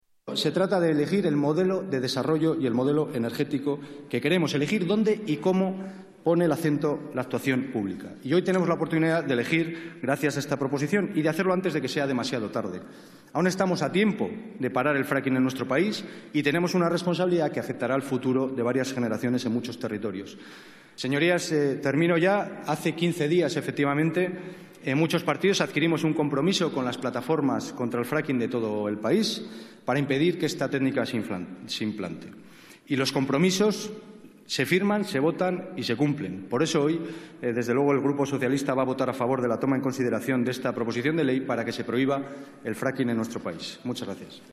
Fragmento de la intervención de Luis Tudanca. Pleno del Congreso.